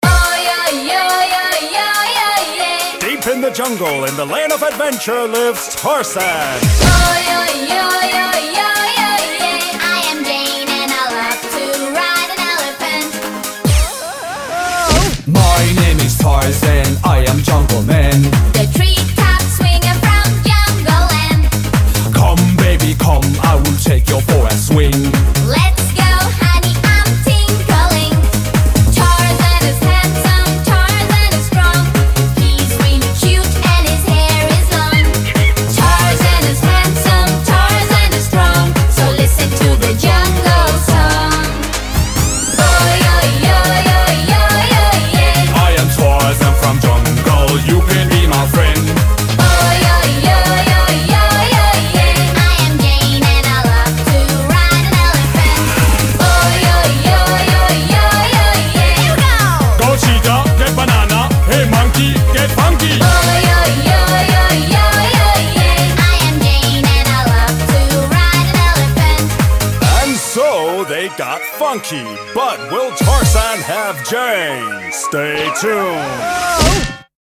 BPM141--1
- Music from custom cut